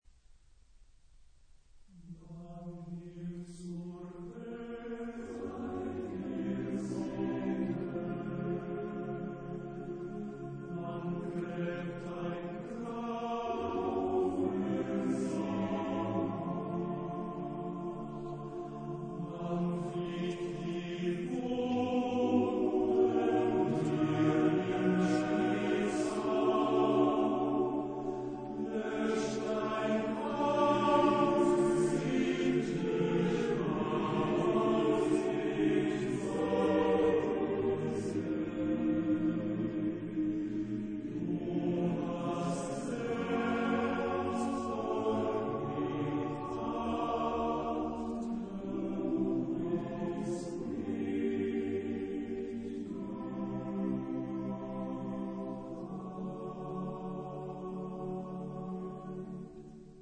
Genre-Style-Forme : Profane ; Lied
Type de choeur : TTBB  (4 voix égales d'hommes )
Tonalité : dodécaphonique